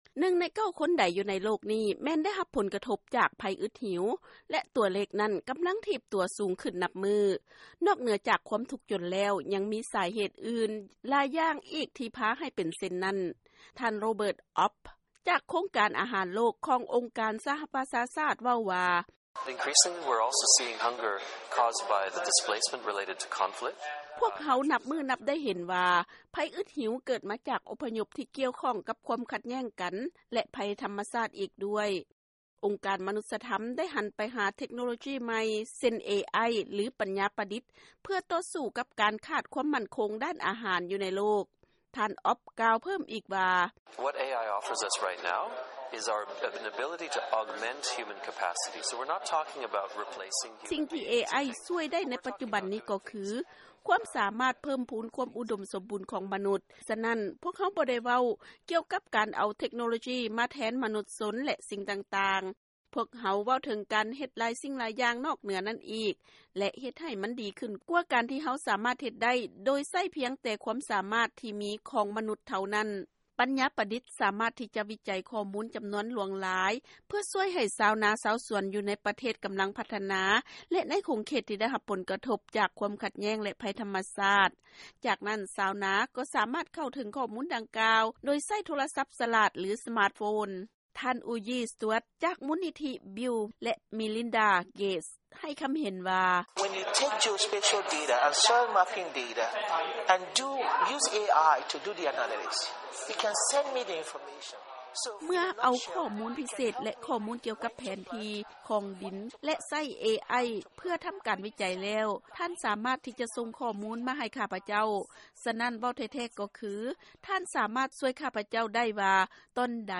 ເຊີນຟັງລາຍງານເລື່ອງປັນຍາປະດິດສາມາດຊ່ວຍຜົນຜະລິດຜັກ ແລະອາຫານອື່ນໆ ໃຫ້ເພີ້ມຂຶ້ນໄດ້